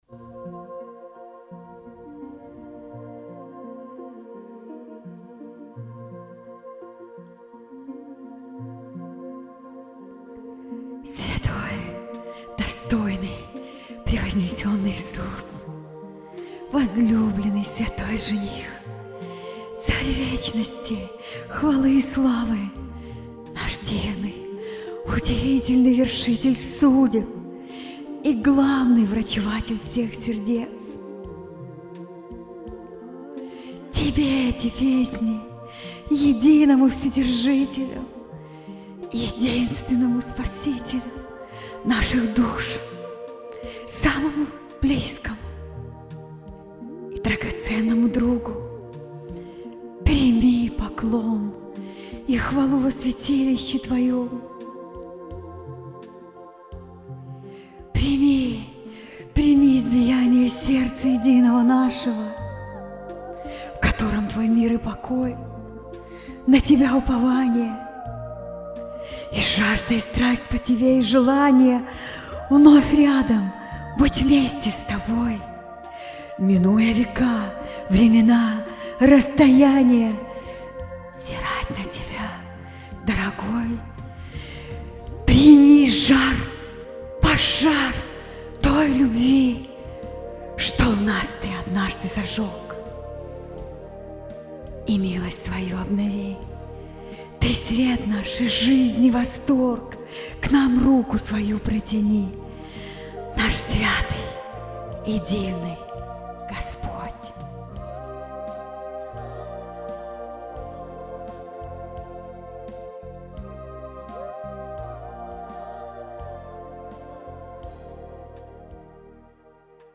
с новыми аранжировками и в новом качестве